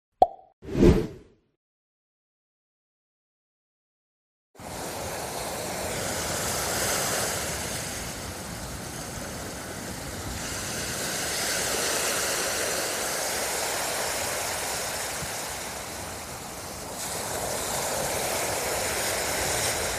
Ocean Waves
Ocean Waves is a free ambient sound effect available for download in MP3 format.
002_ocean_waves.mp3